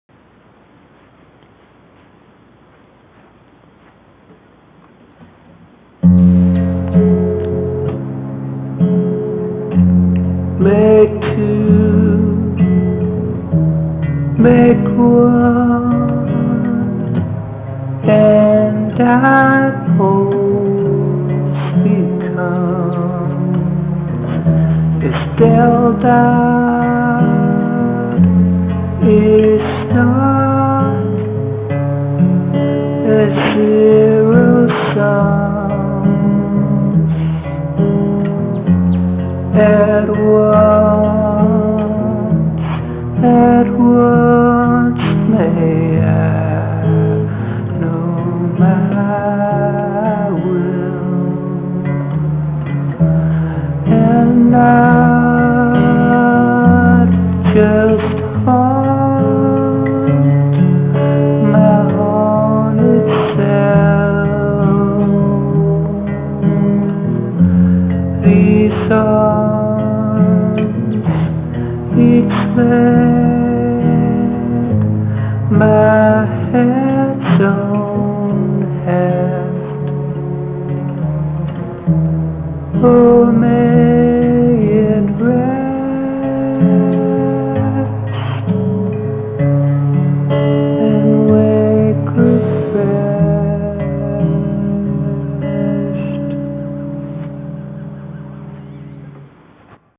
verse: Am, G7, C, E, F, G, Am, C7, F, G7
verse, verse, verse
yeah, I thinks in my nature to sing slowly.